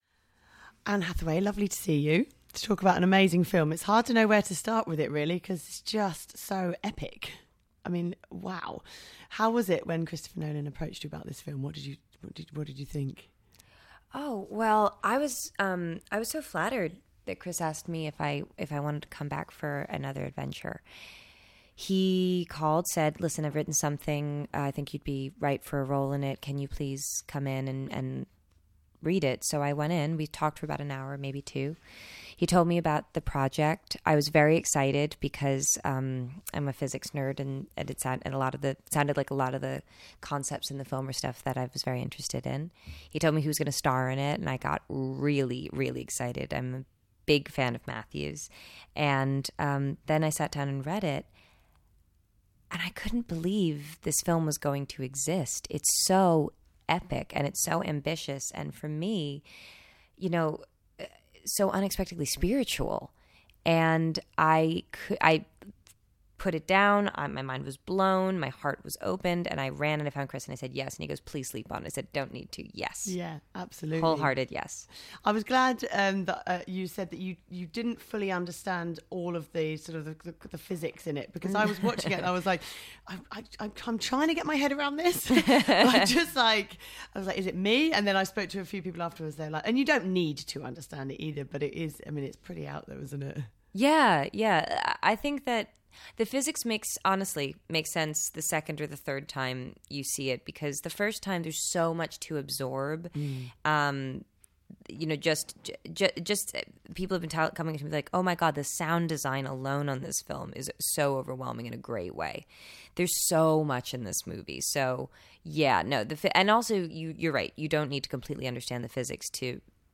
Anne Hathaway Interstellar Interview